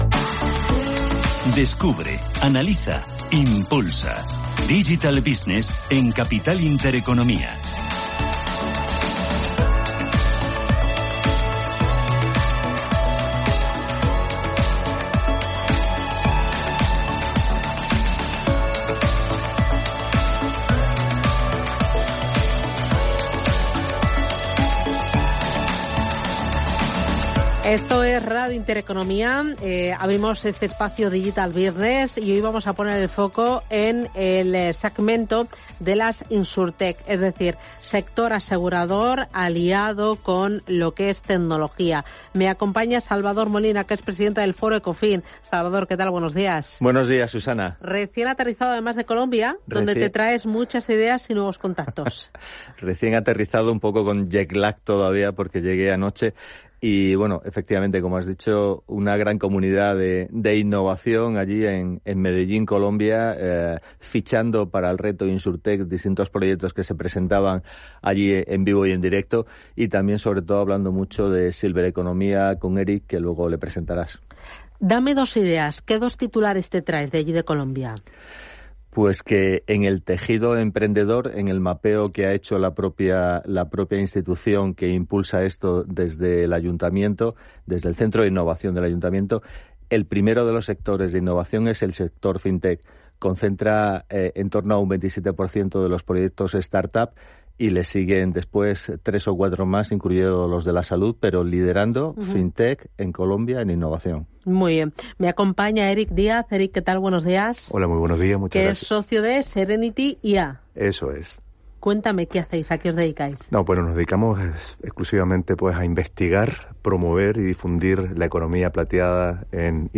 Es “La Hora de las FinTech” en la Radio.